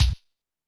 Drums_K4(48).wav